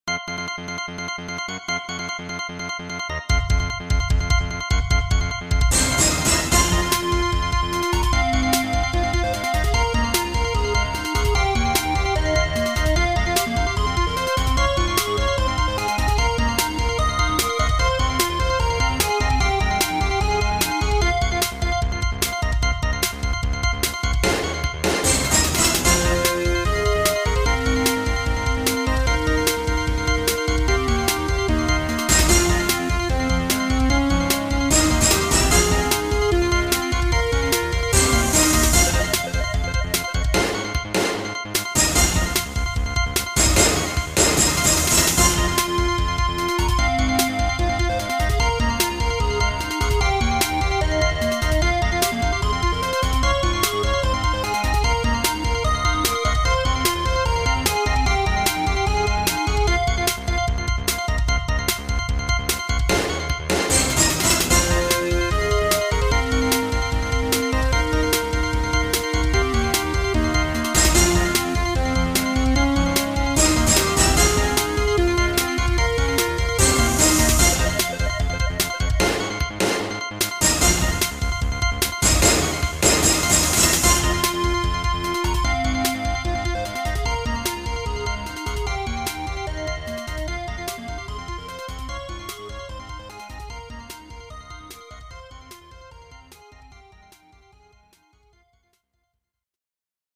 今回初めてドラムパートを二つ使いました。